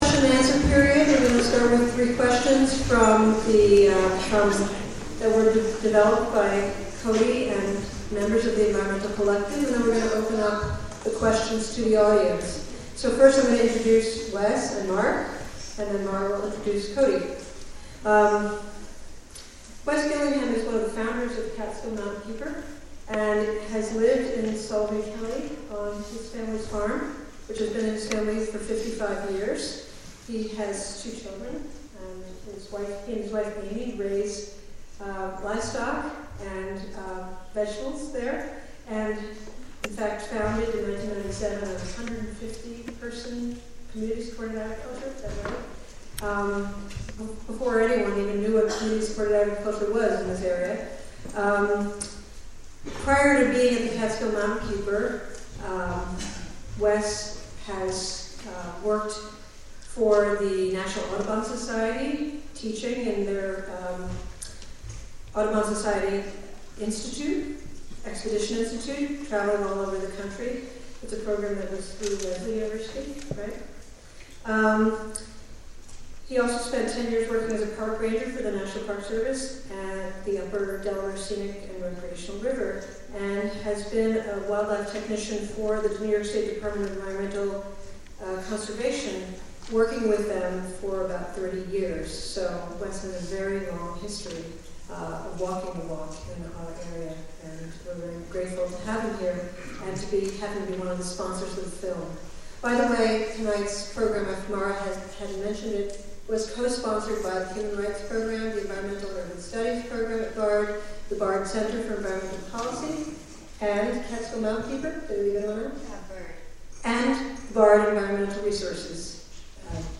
Question and answer discussion after a standing room only, free public screening of the "Gasland" documentary film at Bard's Olin Auditorium